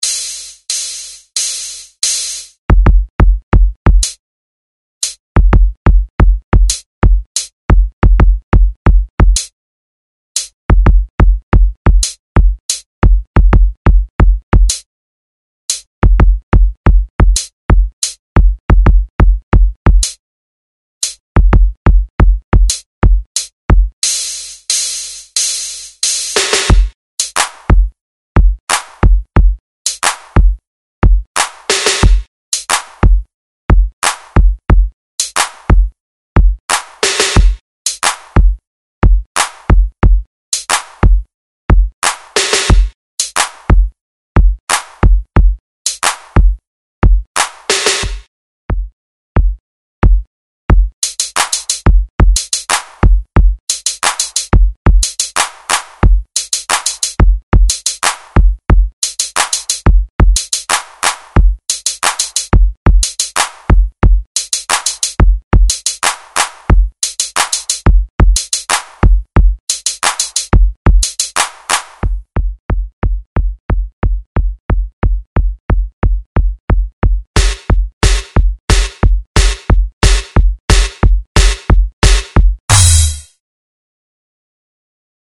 Le Jingle corporel a été inventé par les élèves de l'atelier percussions.
Version lente
Jingle-90BPM.mp3